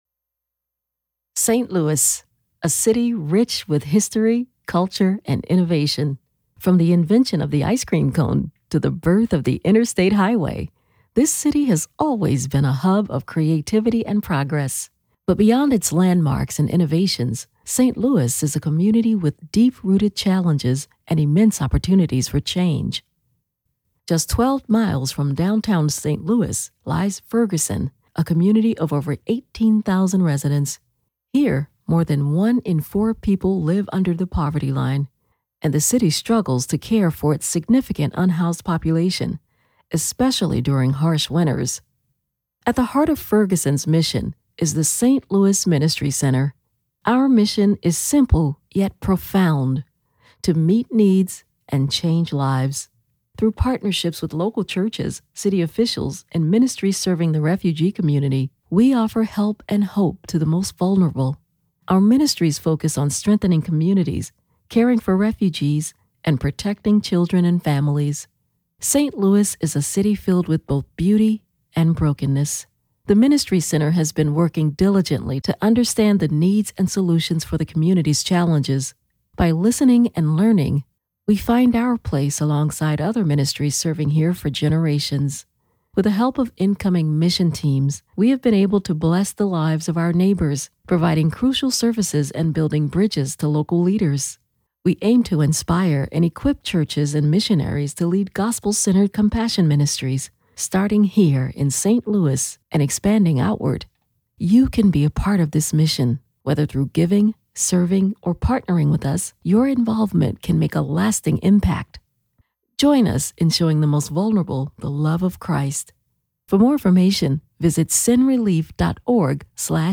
eLearning Demo
American English